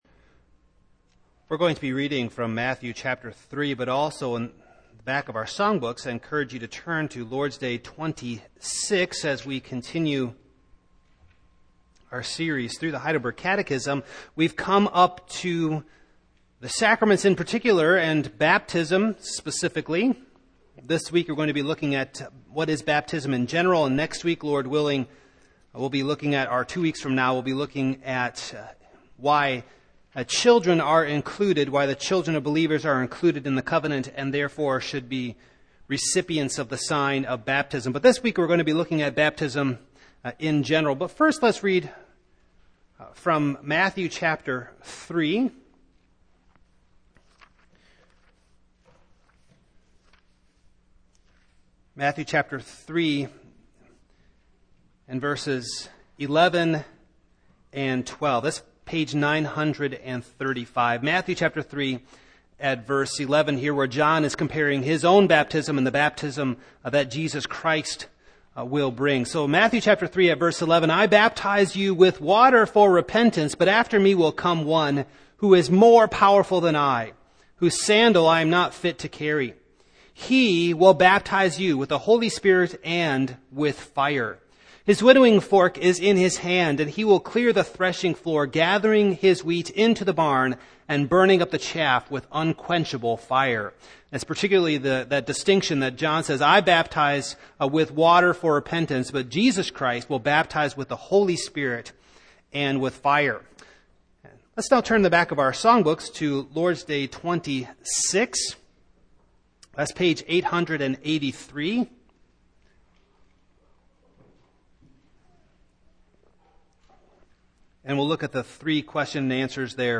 Matthew 3:11-12 Service Type: Evening « Father